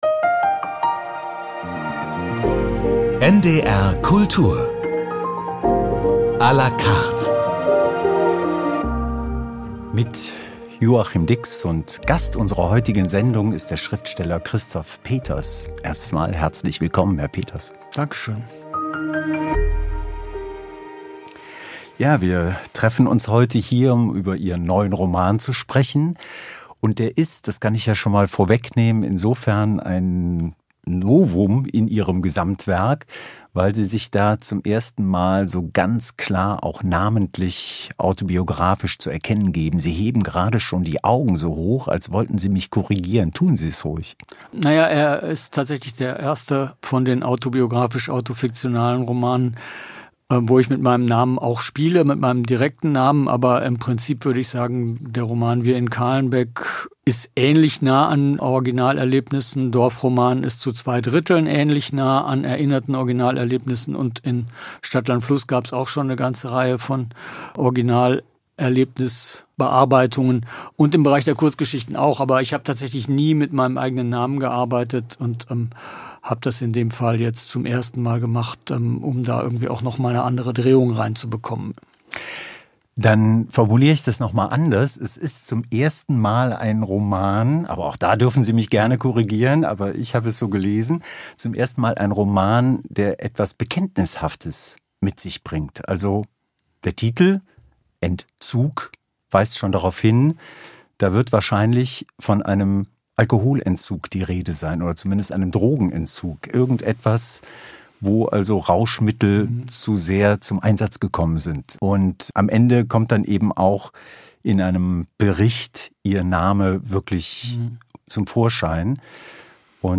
Zu Gast in NDR Kultur à la carte spricht Christoph Peters